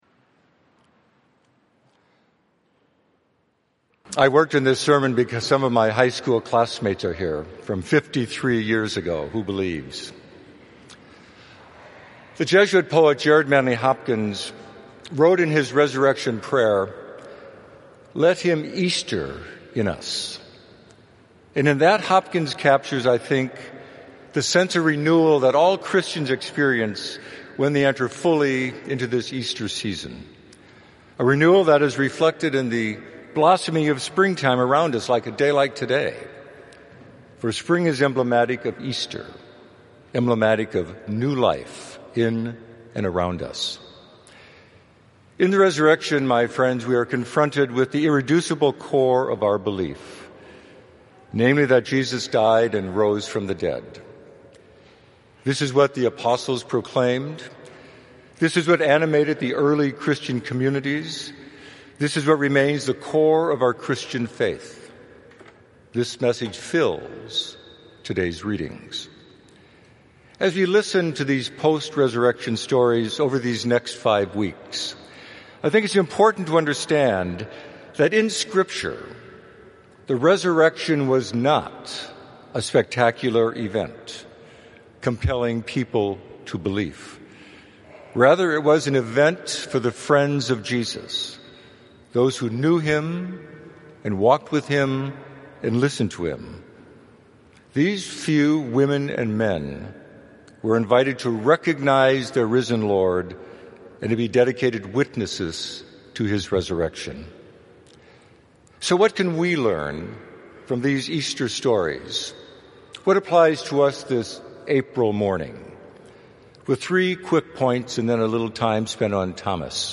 2015 Second Sunday of Easter – 11:30 …